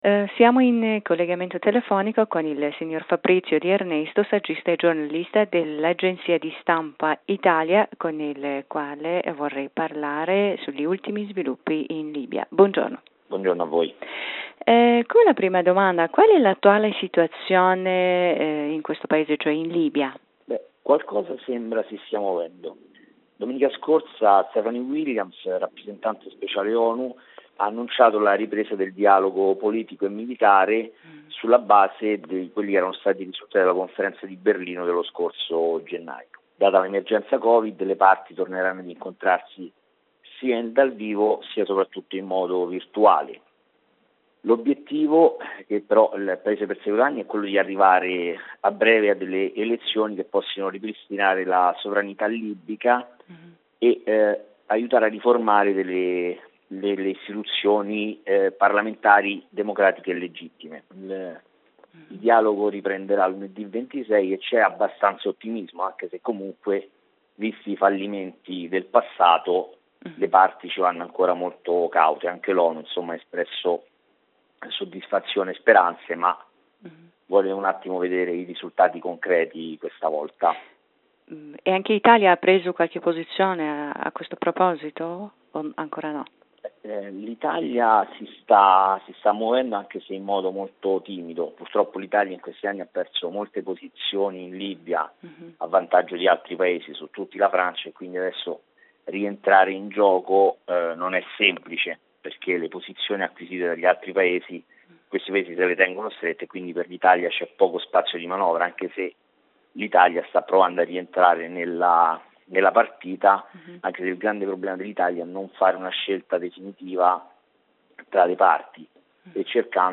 in un collegamento telefonico
Per ascoltare la versione integrale dell'intervista potete cliccare qui sopra: